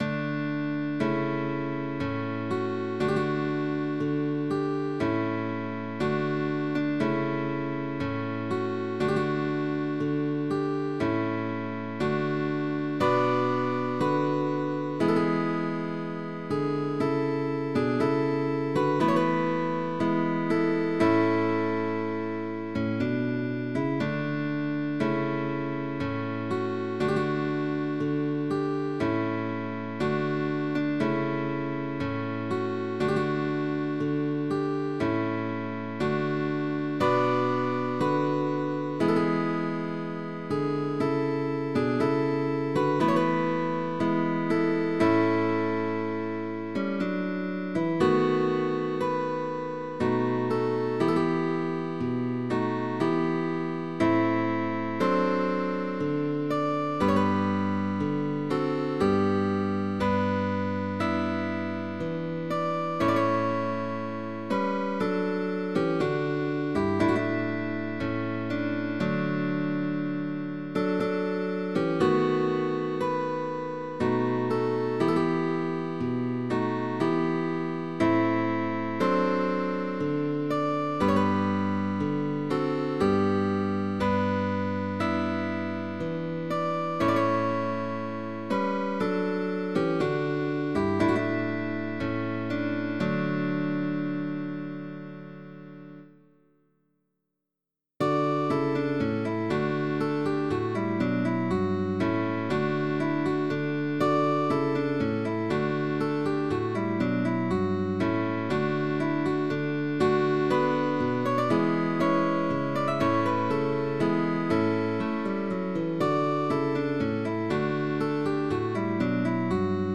Guitar duo sheetmusic
GUITAR DUO